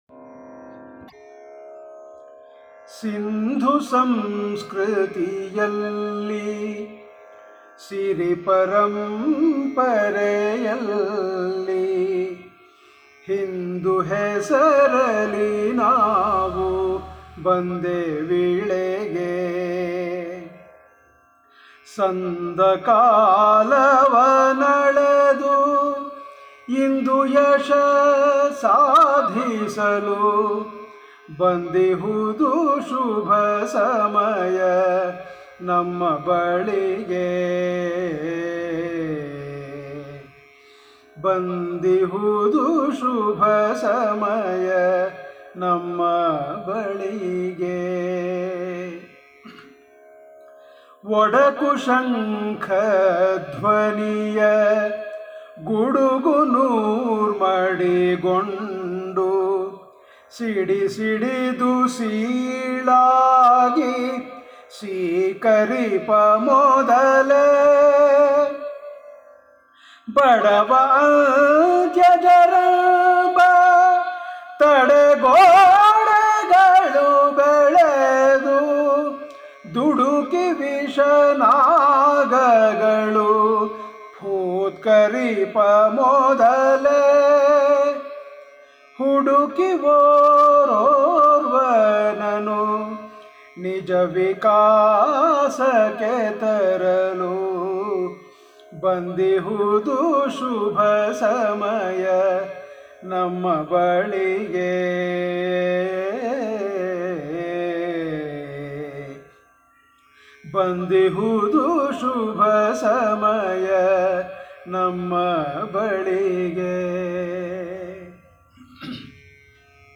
Patriotic Songs Collections
Solo